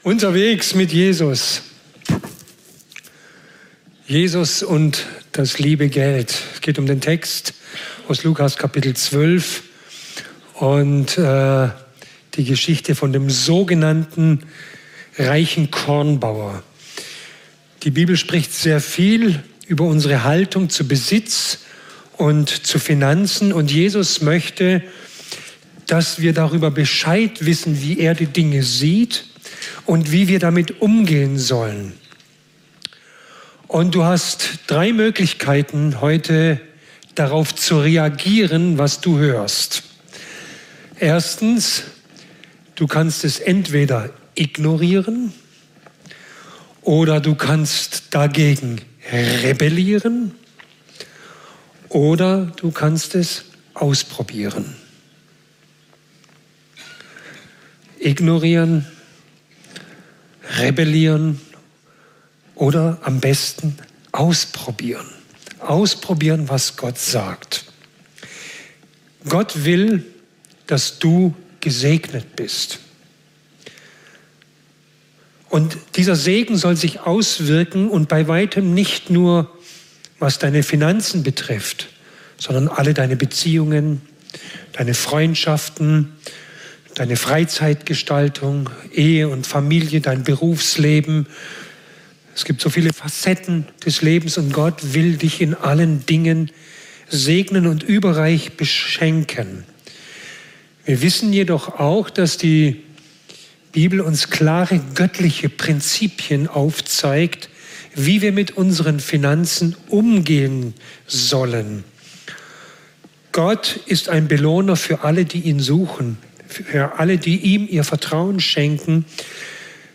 Predigt: Gesunde Gemeinde - Gottes Plan | Unterwegs mit Jesus #21